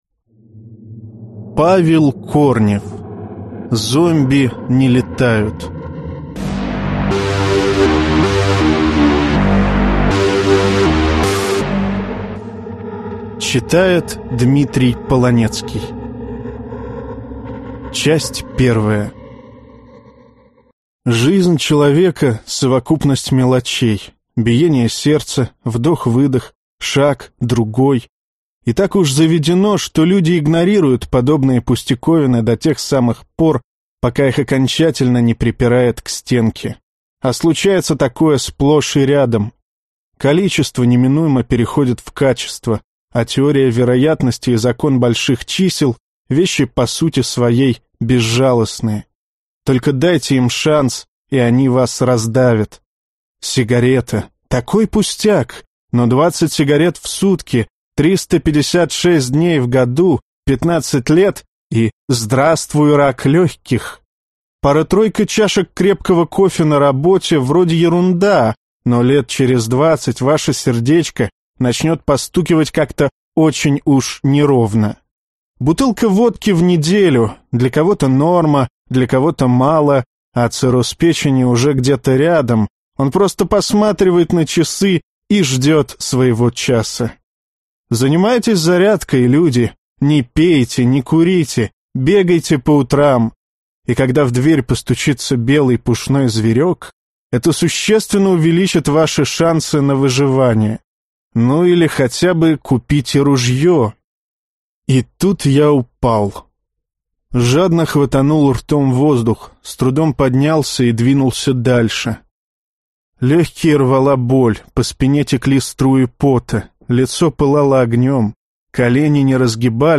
Аудиокнига Зомби не летают | Библиотека аудиокниг